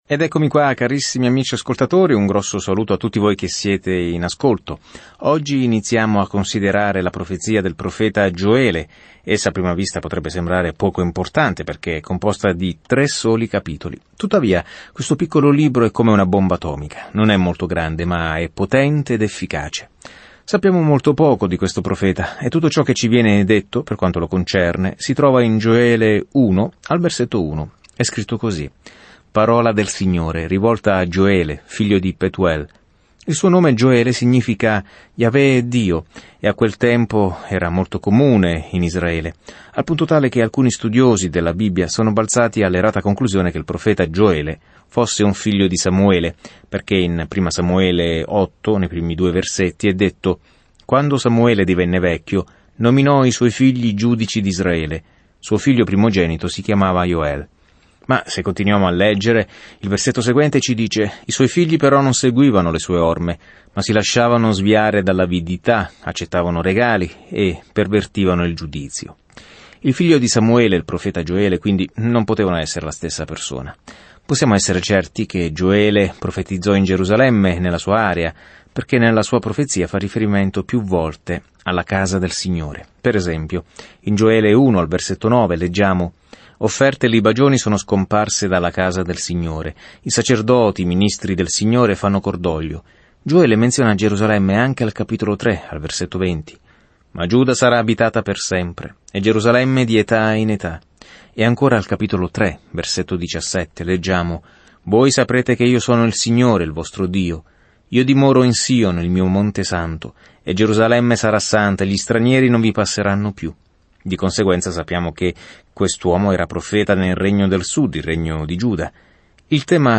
Scrittura Gioele 1:5 Inizia questo Piano Giorno 2 Riguardo questo Piano Dio manda una piaga di locuste per giudicare Israele, ma dietro il suo giudizio c’è la descrizione di un futuro profetico “giorno del Signore” in cui Dio finalmente dirà la Sua. Viaggia ogni giorno attraverso Gioele mentre ascolti lo studio audio e leggi versetti selezionati della parola di Dio.